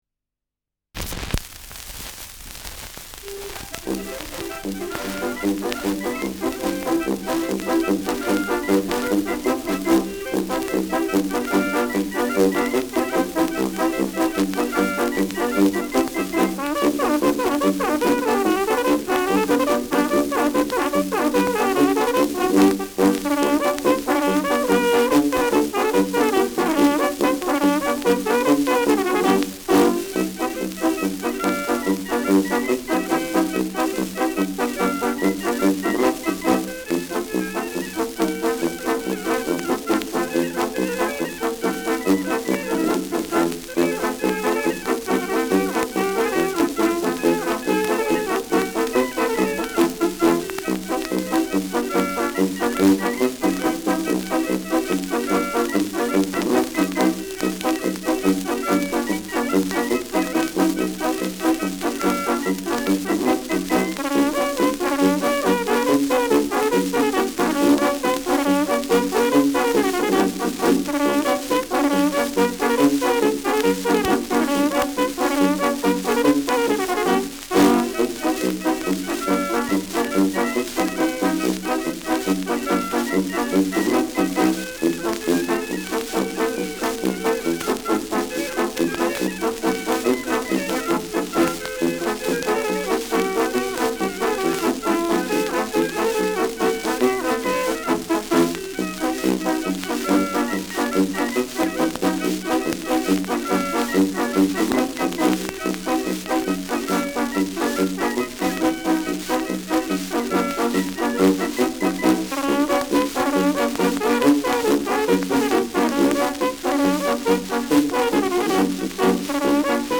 Schellackplatte
Stärkeres Grundrauschen : Gelegentlich leichtes bis stärkeres Knacken : Leichtes Nadelgeräusch
Dachauer Bauernkapelle (Interpretation)